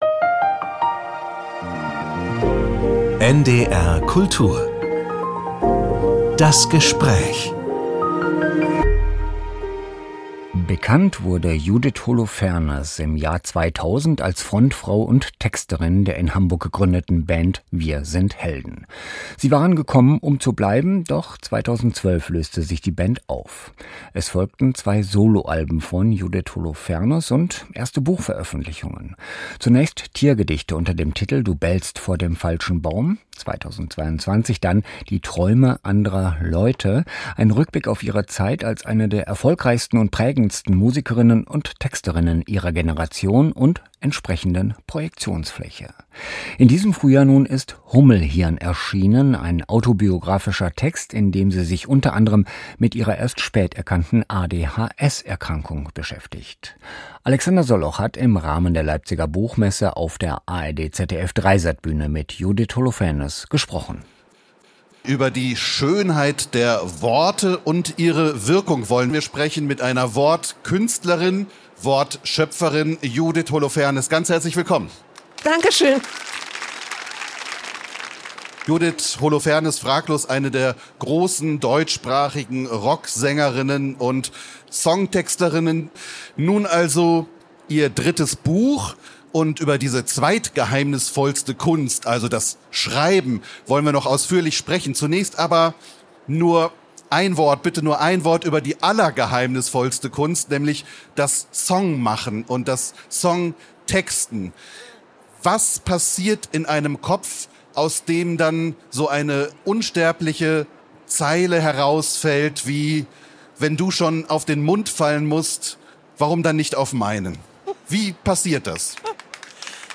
Auf der Leipziger Buchmesse berichtete die frühere "Wir sind Helden"-Sängerin und -Texterin offen und ehrlich von ihrem wilden Leben mit ADHS.